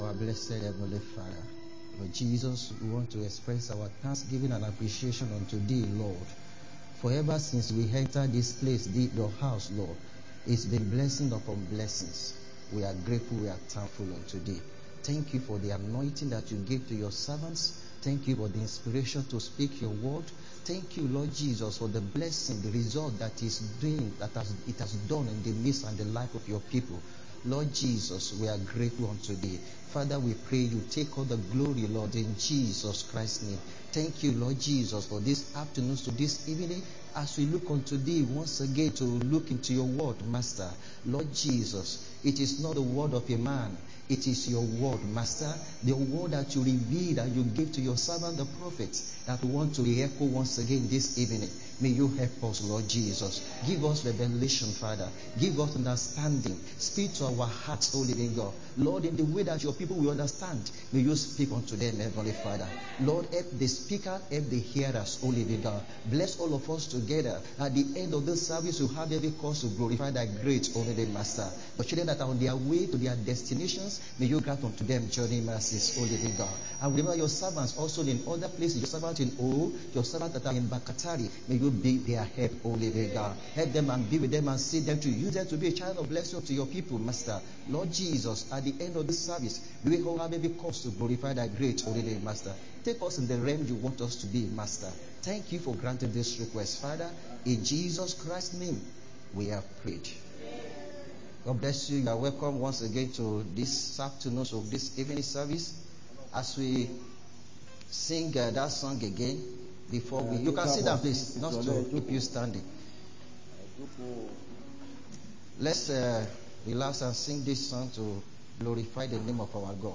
Sunday Afternoon Service